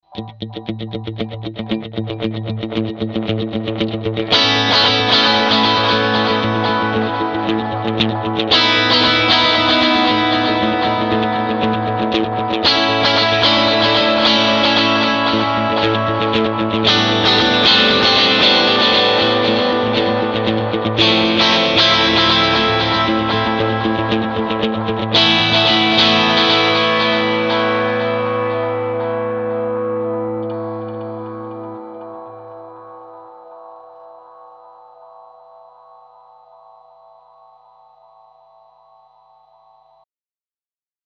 Soundbeispiele des Walrus Audio Mako D1
Alle Beispiele wurden mit meiner Fender Stratocaster und meinem Mesa Boogie Mark V 25 über den CabClone D.I. aufgenommen.